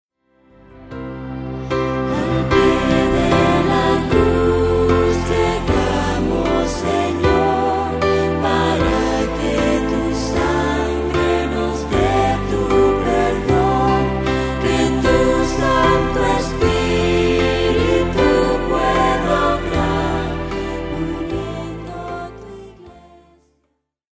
Demos